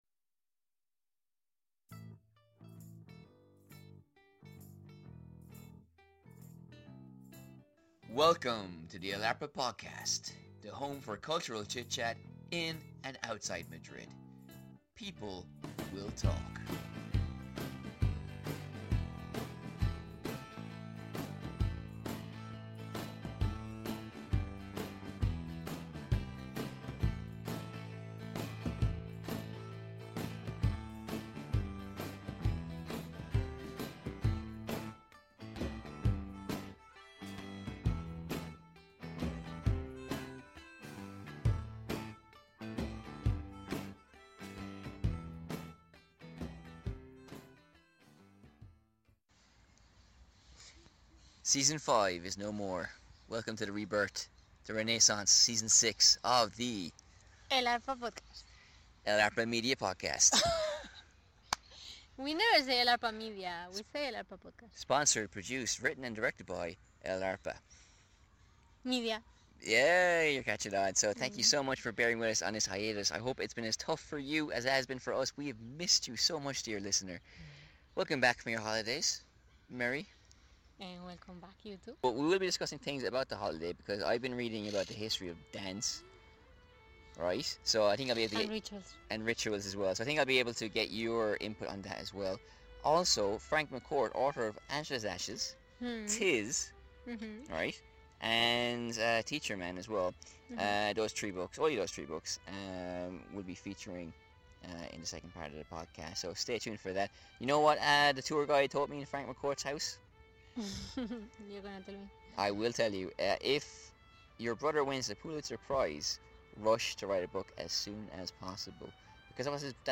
Join us as we visit Frank McCourt's house in Limerick and discuss the writer's brief but essential set of works.